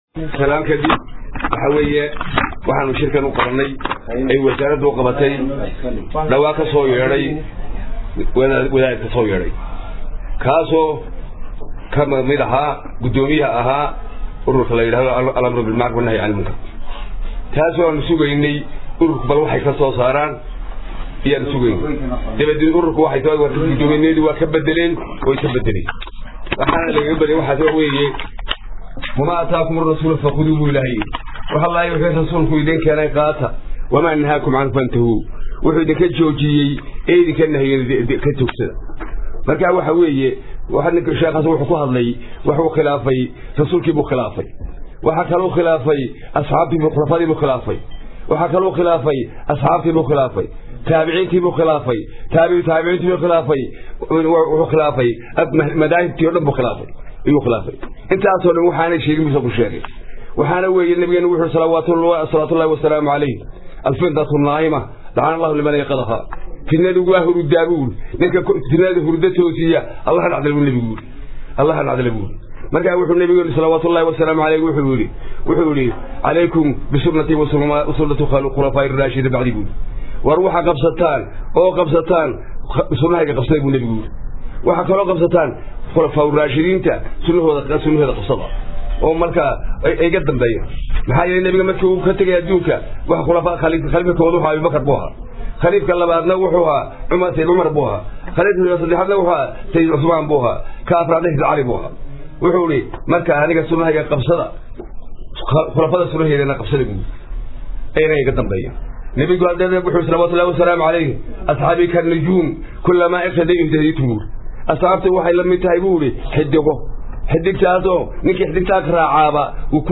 Hargaysa 31.March 2014 (SDN)- Wasaaradda Diinta Iyo Awqaafta Somaliland ayaa maanta ka hadashay hadalkii dhawaan kazoo yeedhay sheikh ka mida culimadda Magaalada Hargaysa oo sheegay in ay siman tahay maggta ragga iyo dumarka marka laga eegayo dhinaca Diinta Islaamka waxaana masuuliyiinta Wasaaradda Diinta iyo Awqaaftu ay kaga hadleen shir jaraaid oo ay ku qabteen xarunta wasaaradaasi kaasoo ay kazoo saareen war saxaafadeed ay kaga hadlayaan waxa ay wasaarad ahaan ka qabaan talaabadda sheekhaasi ku kacay, waxaanu u dhignaa war saxaafadeedku sidan.